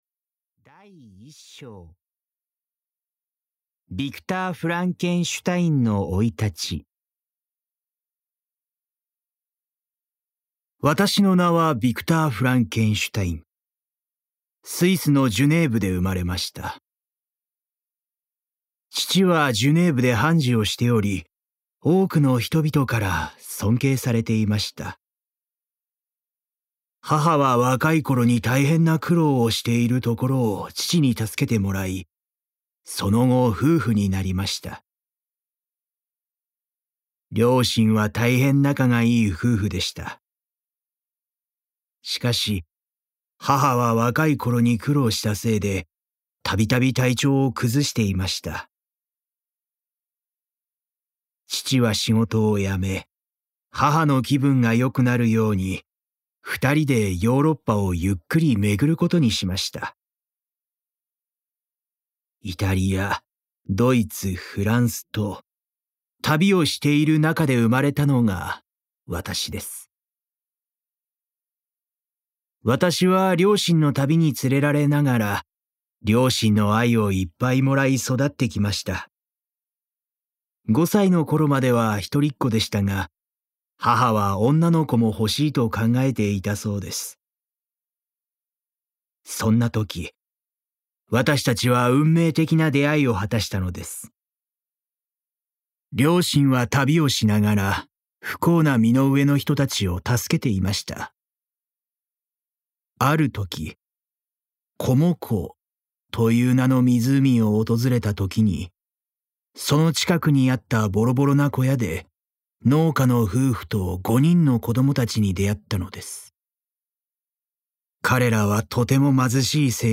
[オーディオブック] フランケンシュタイン（こどものための聴く名作 8）